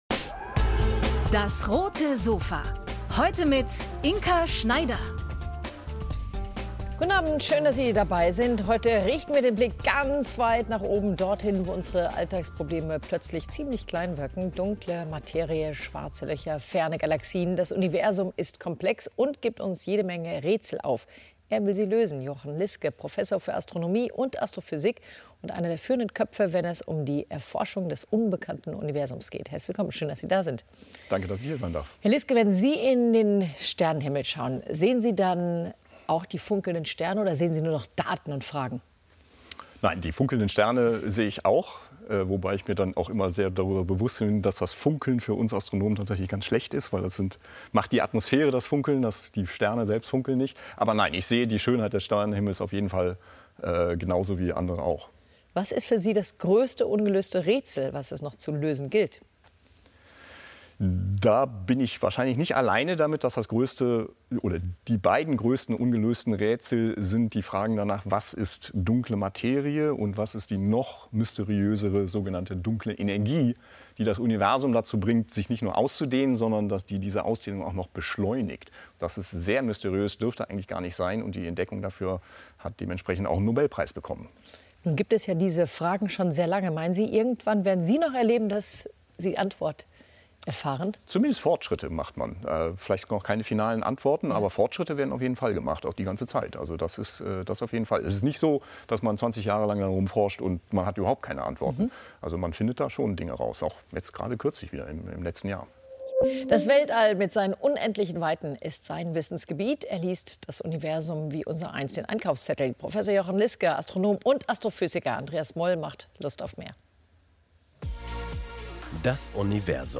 DAS! - täglich ein Interview Podcast